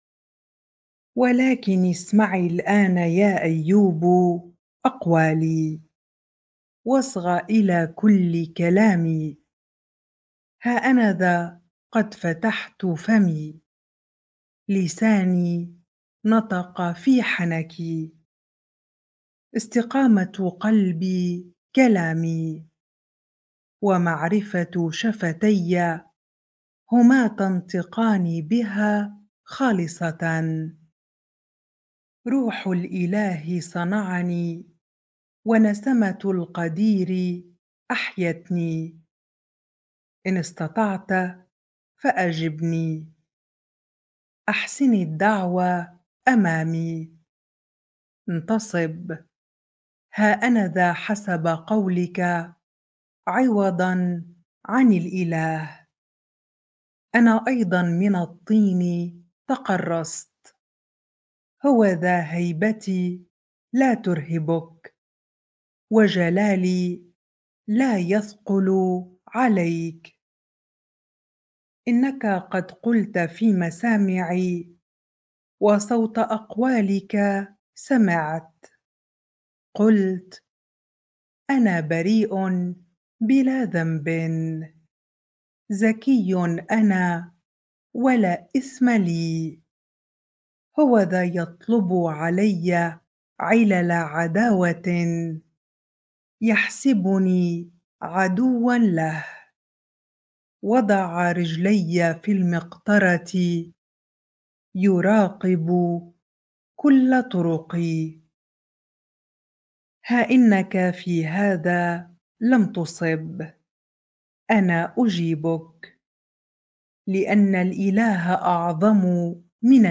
bible-reading-Job 33 ar